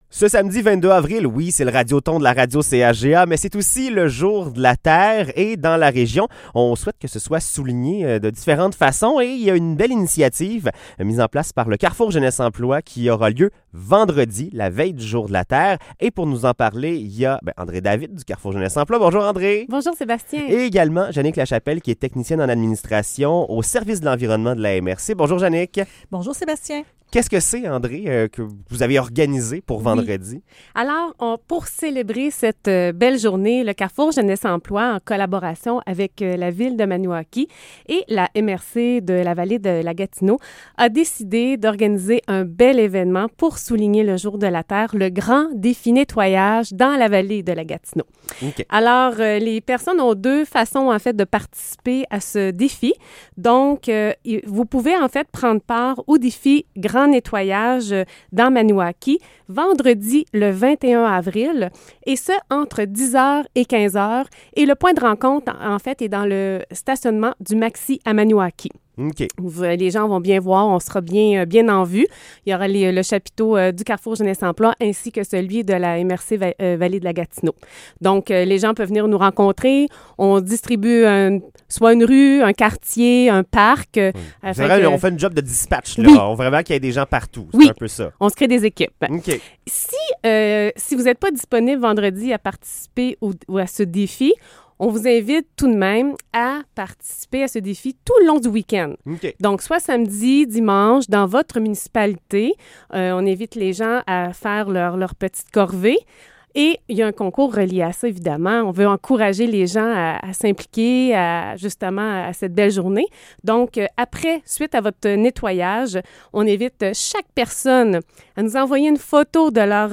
Entrevue sur le défi nettoyage du Jour de la Terre
entrevue-sur-le-defi-nettoyage-du-jour-de-la-terre.mp3